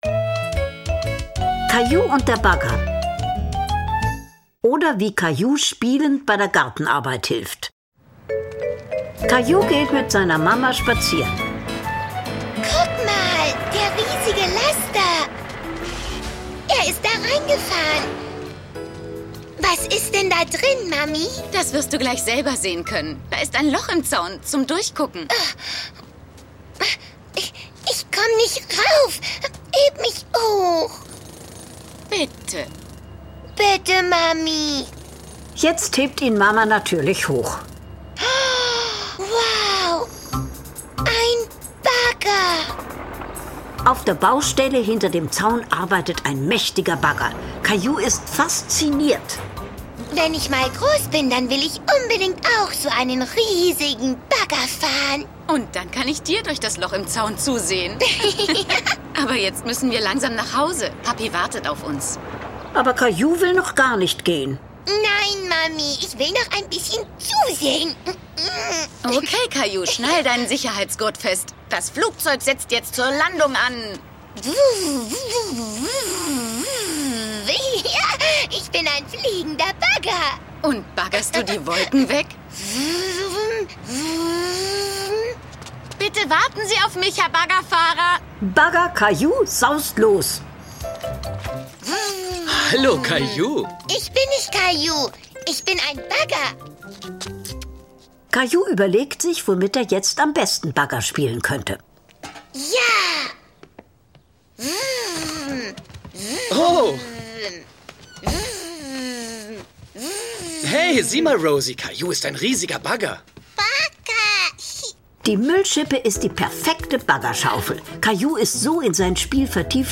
Caillou - Folgen 25-37: Caillou und der Bagger - Hörbuch